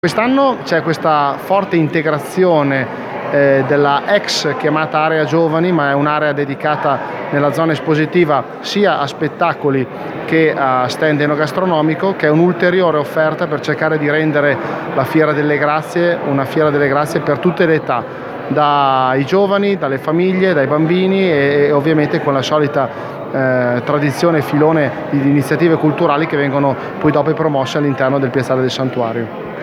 Novità del 2019 sarà lo spazio “Avis village” con un calendario di proposte musicali, culinarie a cura delle Avis Mantovane, come confermato dal vicesindaco di Curtatone, Federico Longhi: